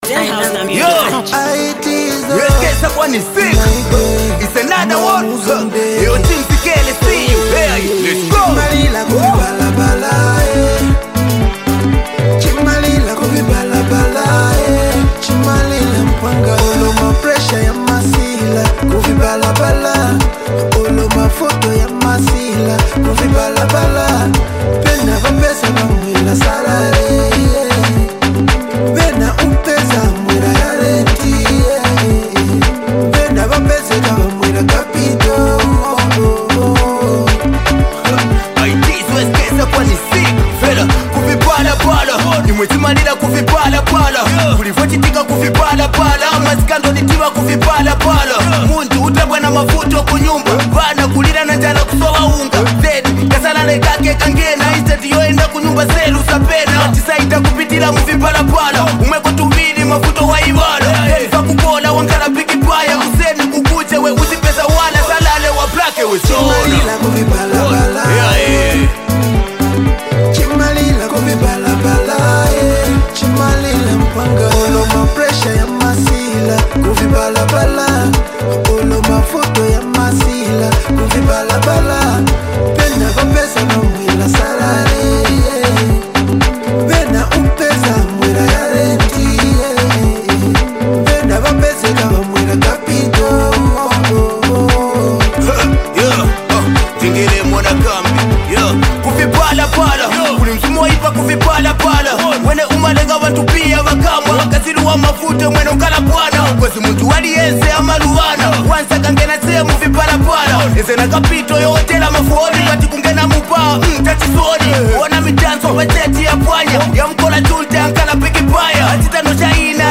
club banger
With infectious beats, energetic vibes
this track is a certified party anthem.